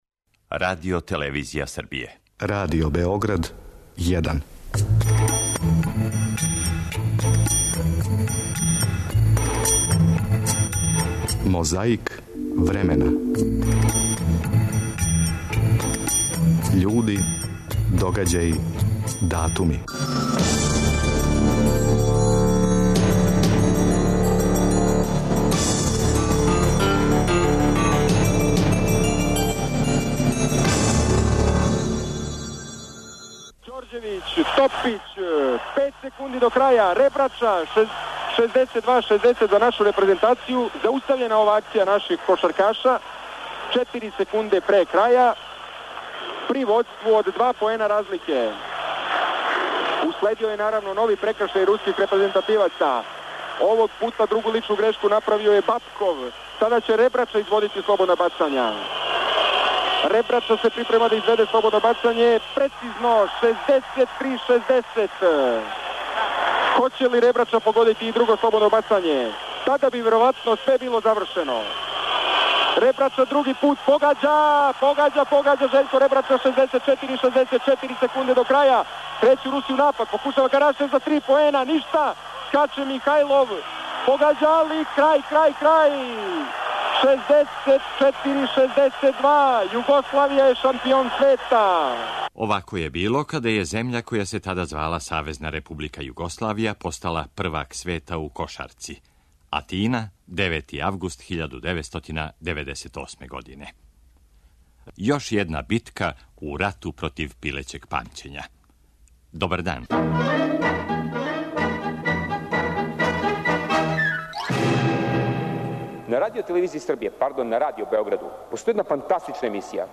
Издвајамо и говор Јосипа Броза Тита, који је на Жабљаку, 7. августа, пре 31 годину, говорио на Осмој конференцији Комунистичке партије Црне Горе. 6. августа 1970. почела је изградња Белог Двора, према пројекту архитекте Александра Ђорђевића.